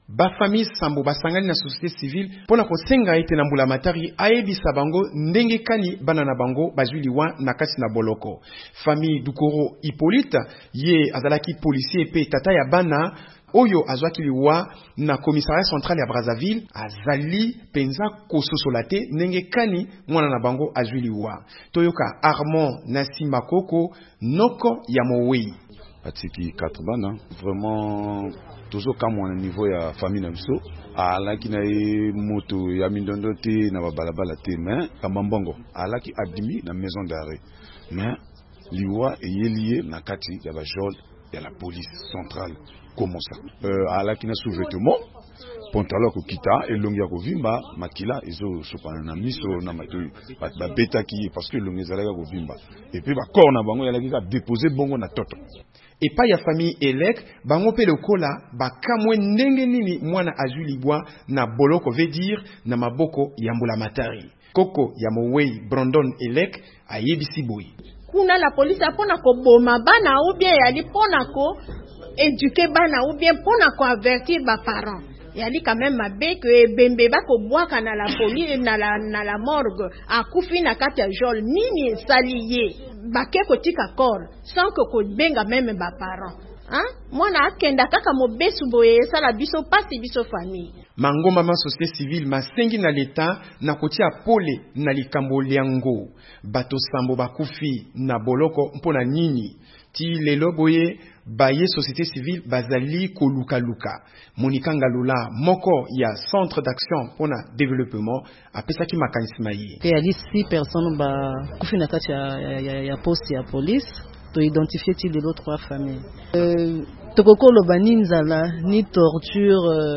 Mabota sambo basangani na ba ONG mpo na kosenga biyano na mbulamatari nsima na liwa lya bana ba bango na bokangami na commissariat central ya police na Brazzaville. Reportage